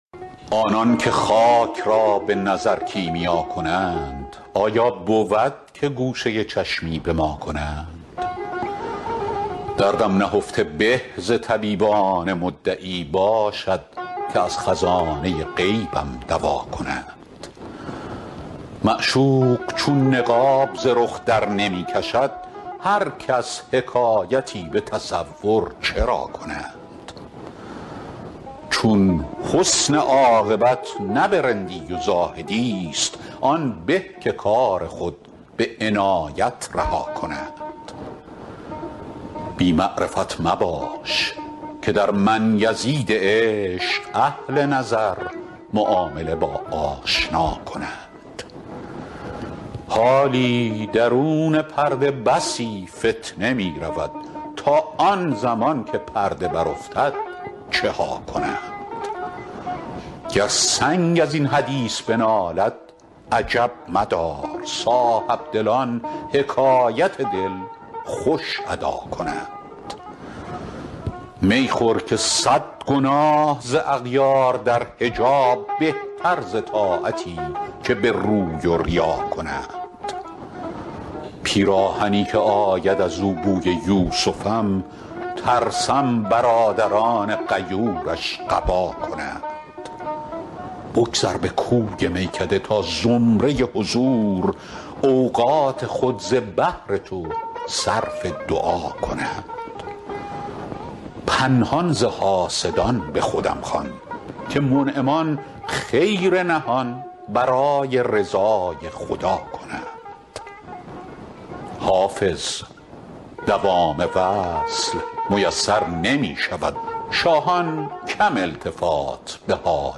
حافظ غزلیات غزل شمارهٔ ۱۹۶ به خوانش فریدون فرح‌اندوز پخش قبلی پخش بعدی توقف بی‌صدا حداکثر صدا تکرار نیاز به بروزآوری جهت خوانش شعر شما یا باید از مرورگر جدیدی همچون فایرفاکس استفاده کنید یا افزونه فلش مرورگرتان را بروزآوری کنید.